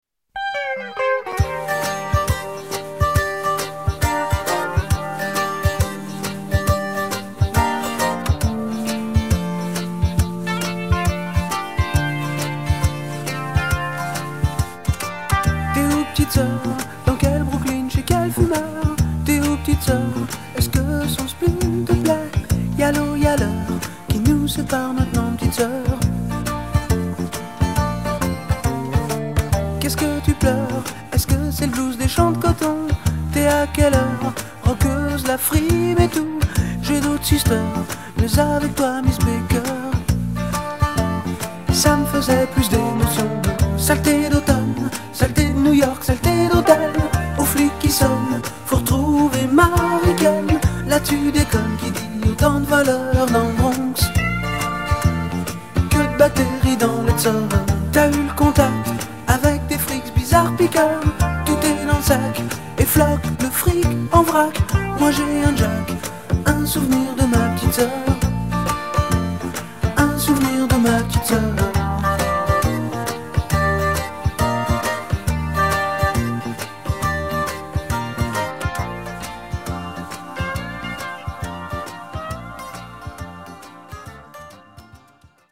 tonalité SIb majeur